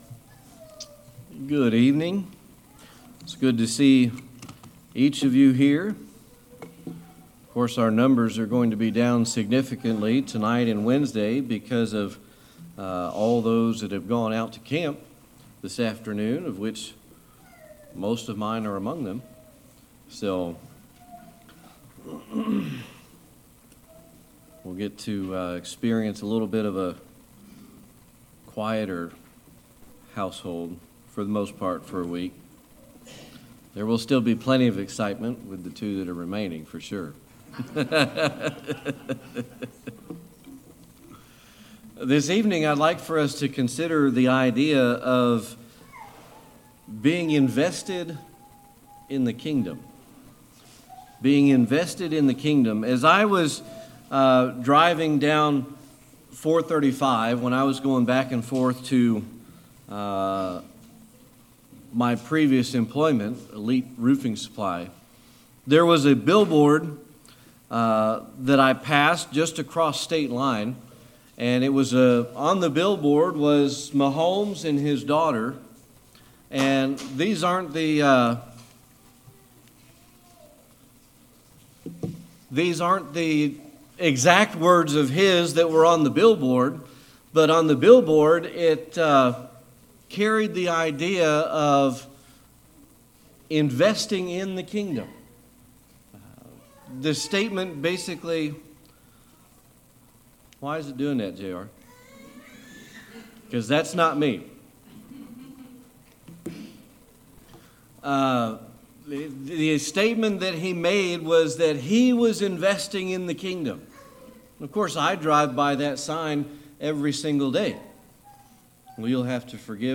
Series: Sermon Archives
Service Type: Sunday Evening Worship